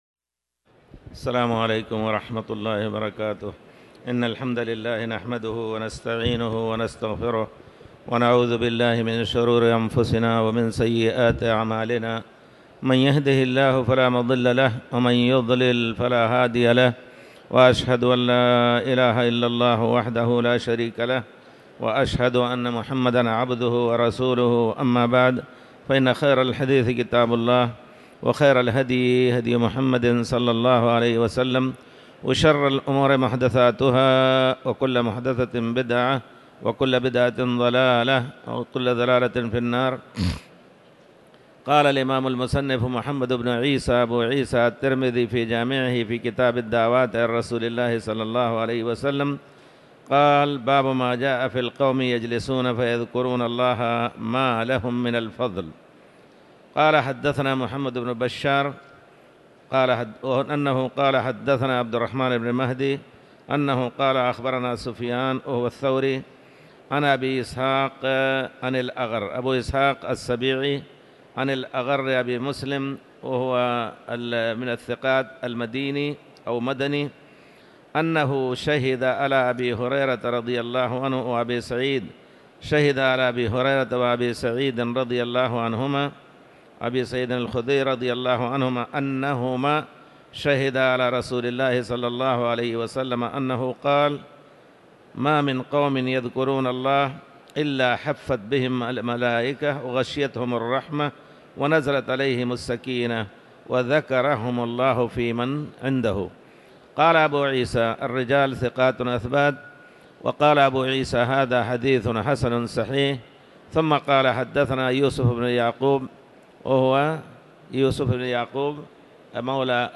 تاريخ النشر ١١ جمادى الأولى ١٤٤٠ هـ المكان: المسجد الحرام الشيخ